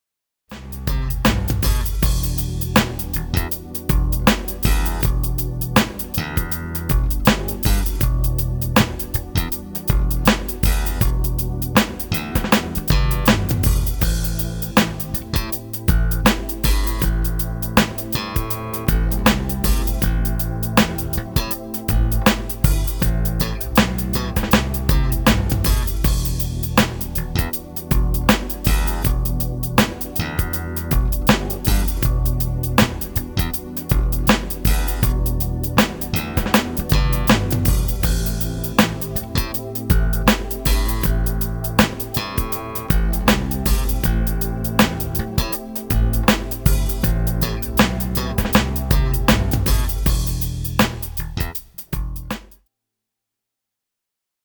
Precision Bässe ▷ Der User-Thread
Hier mal eine Aufnahme davon, wobei die Saiten nicht mehr frisch sind ...